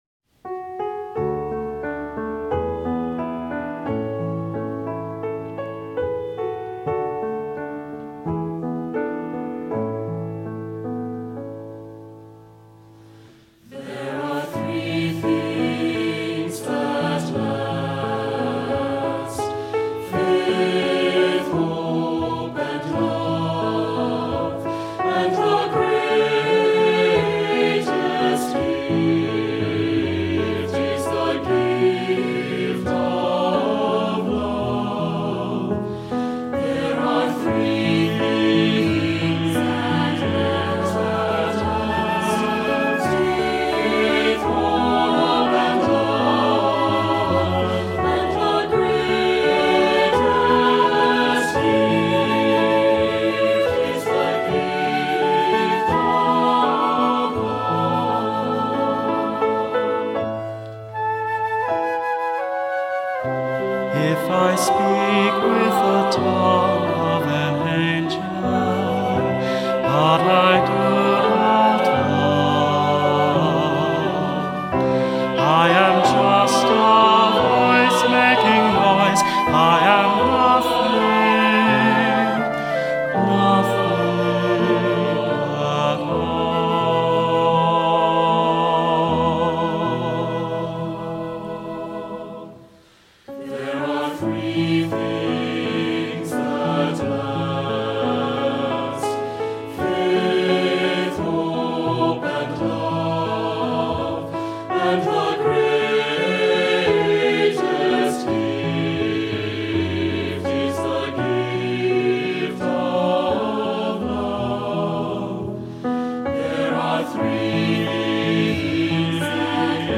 Voicing: Cantor